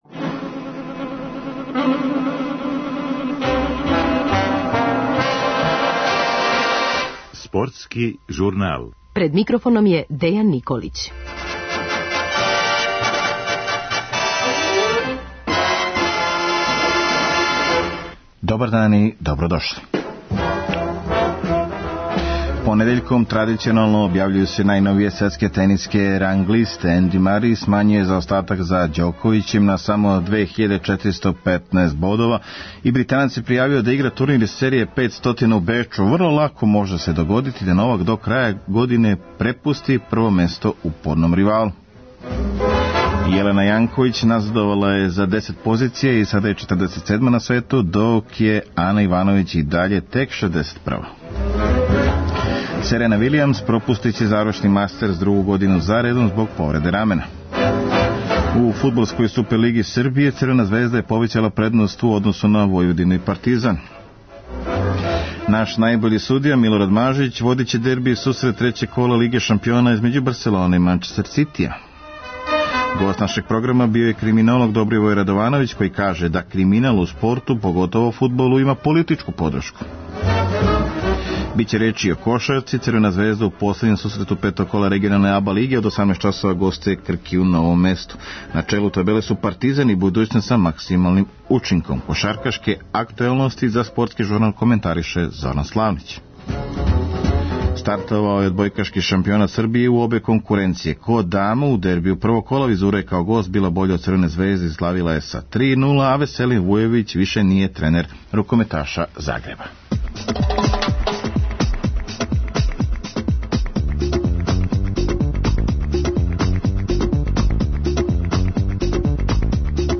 Говорићемо о актуелном дешавању у тенису фудбалу, кошарци. А гост наше емисије биће прослављени кошаркашки ас Зоран Славнић.